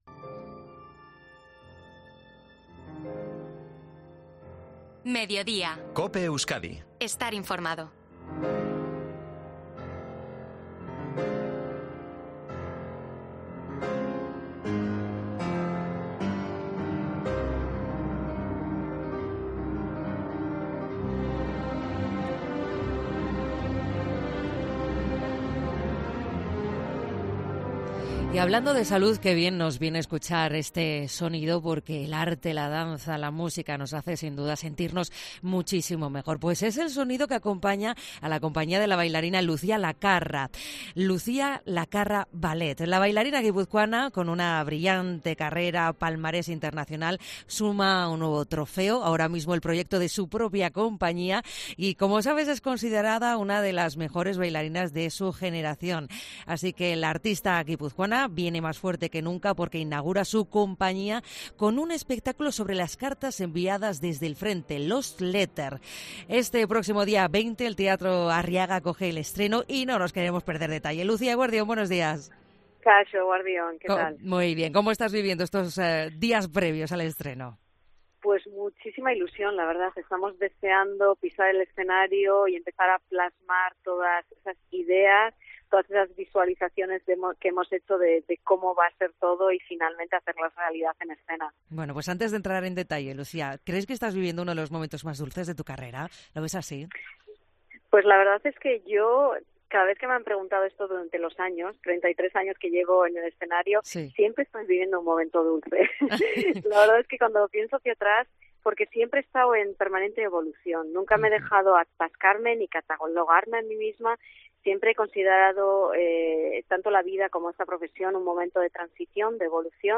Entrevista a Lucía Lacarra, en COPE Euskadi